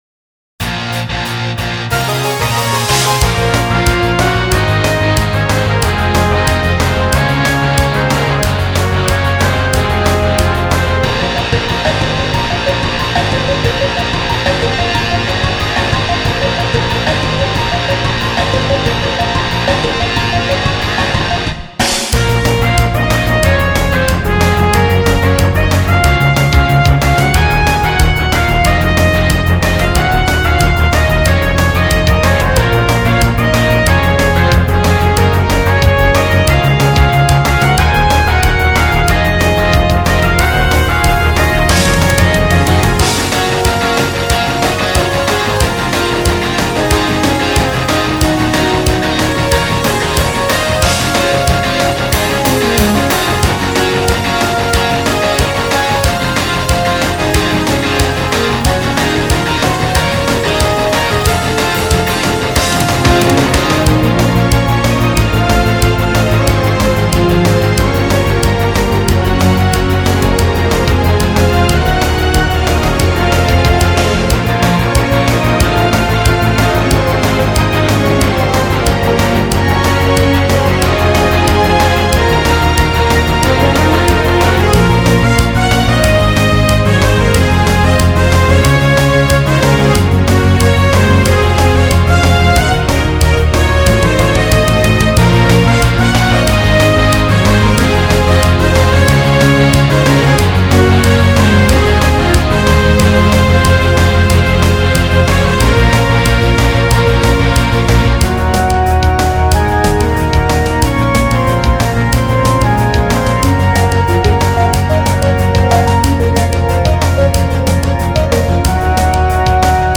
かわりに音質は若干落としてます。
ギュワギュワとピコピコの対比をお楽しみください。
六戦鬼のテーマの主旋律をアレンジして金管楽器でやってます。
彼女は水晶をモチーフにキャラデザインしてるのでキラキラと電子音。
ストリングスは彼女のイメージに合っていたので、熱情っぽいかんじで。
金管とシンセの定番構成でゲームっぽさをアップ。
ピコピコは魔法人形をイメージしてます。
なので、次から次にせまりくるメロディというありそうであまりない構成にしたのですが
サンプラーをいつもより駆使してつくったので他の曲よりも時間が若干かかったのですが
久しぶりにパワーコード多用しちゃったよぉ～。